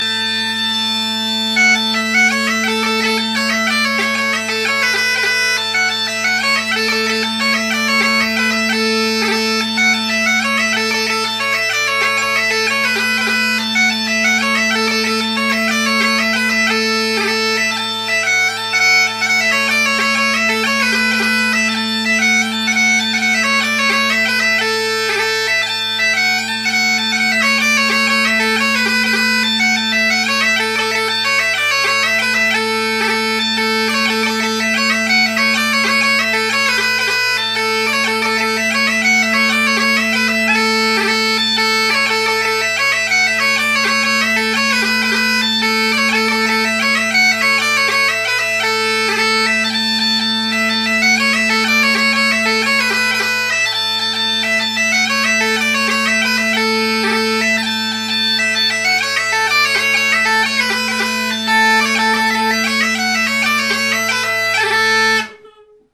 New pipes (sort of) – Bedroom
Great Highland Bagpipe Solo
Here’s my 3/4 Center drones playing Wygent A440 drone reeds coupled with an EJ Jones border/highland chanter: